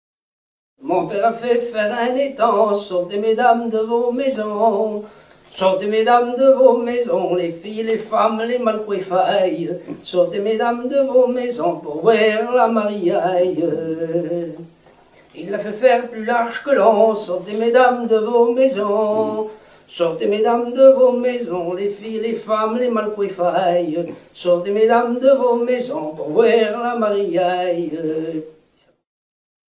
gestuel : à marcher
circonstance : fiançaille, noce
Genre laisse